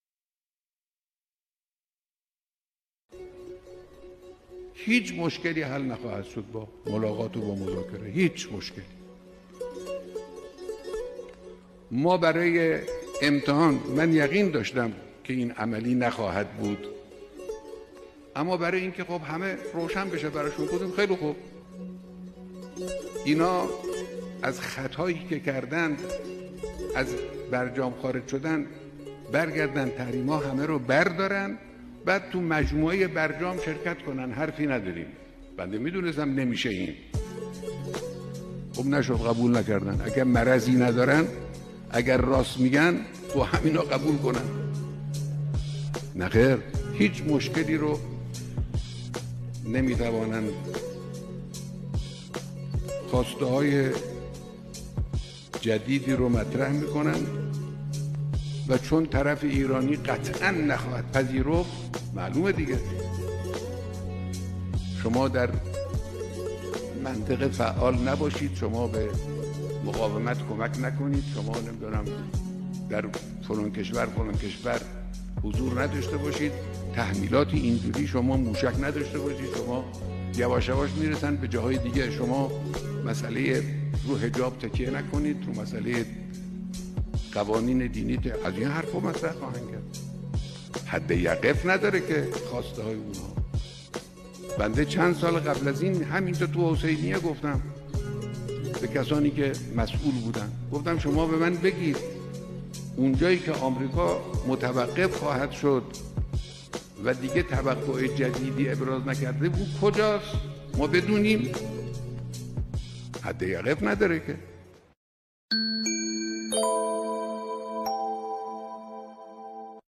به گزارش خبرگزاری حوزه، مقام معظم رهبری در یکی از سخنرانی ها به موضوع «حد یقف خواسته‌های تحمیلی آمریکا» اشاره کردند که تقدیم شما فرهیختگان می‌شود.